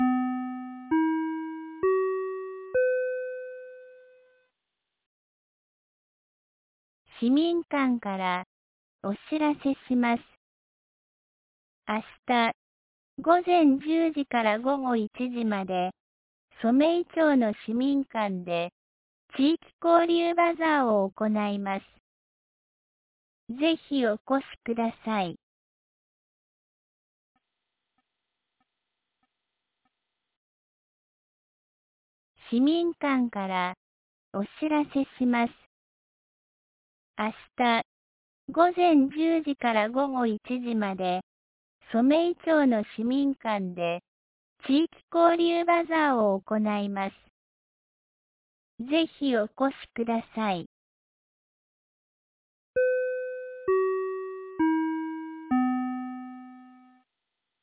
2024年12月14日 17時10分に、安芸市より全地区へ放送がありました。